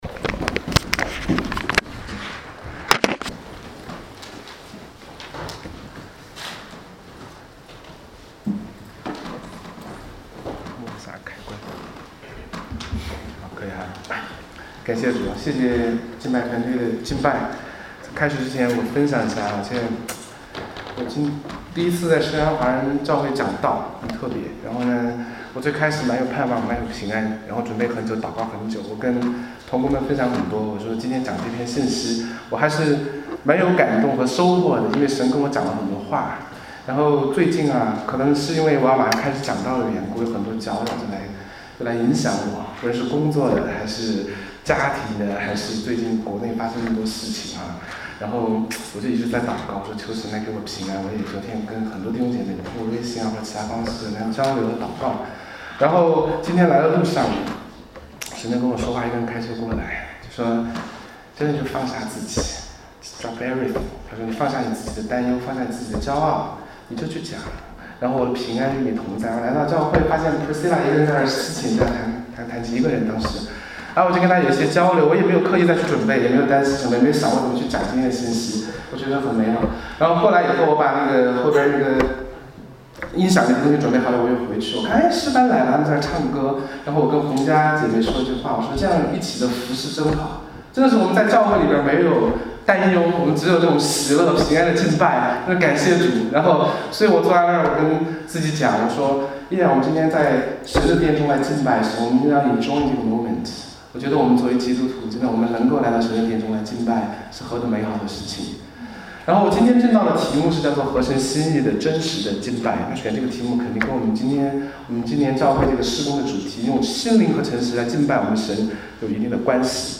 Special Topic Passage: John 4:23-24, Romans 12:1-2 Service Type: Sunday Service 約翰福音4:23-24 CUV 23時候將到，如今就是了，那真正拜父的，要用心靈和誠實拜他，因為父要這樣的人拜他。